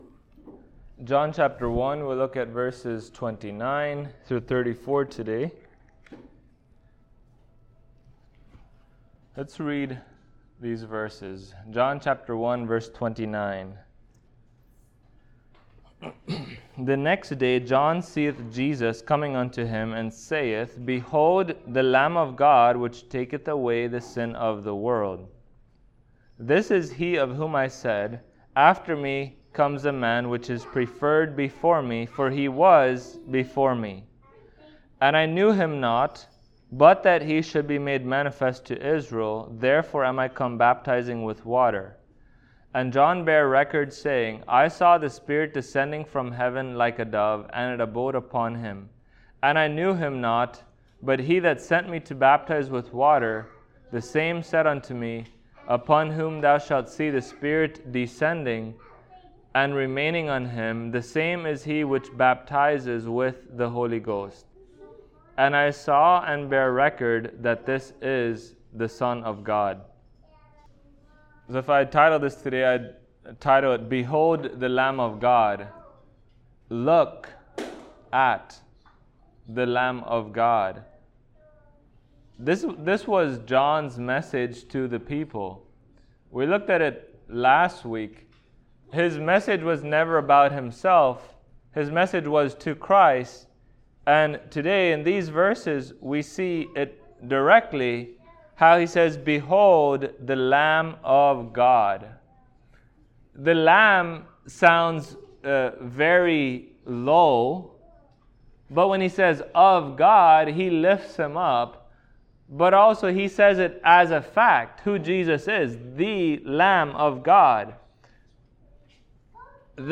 John Passage: John 1:29-34 Service Type: Sunday Morning Topics